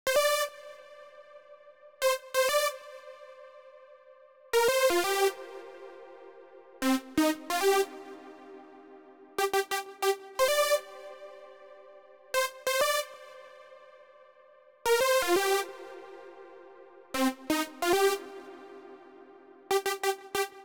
08 synth A.wav